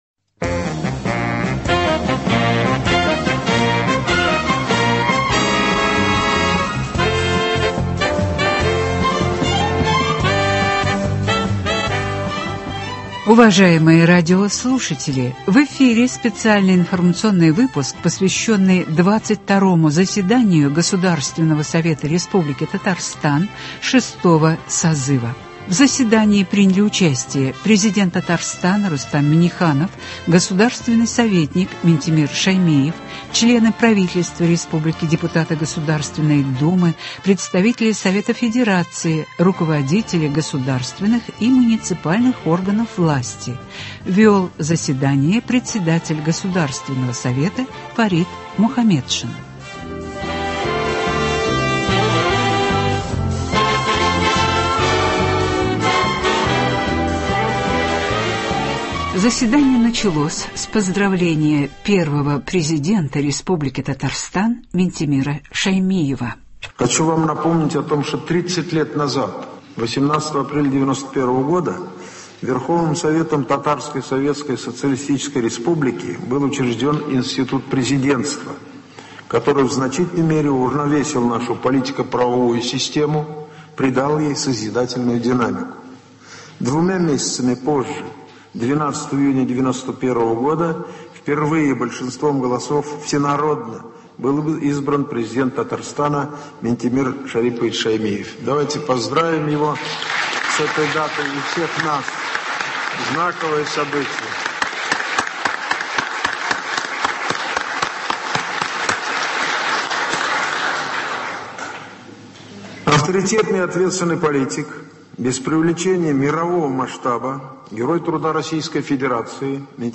Радиоотчет о заседании Госсовета (11.06.21) | Вести Татарстан
В эфире специальный информационный выпуск , посвященный 22 заседанию Государственного Совета Республики Татарстан 6-го созыва.